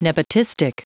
Prononciation du mot nepotistic en anglais (fichier audio)
Prononciation du mot : nepotistic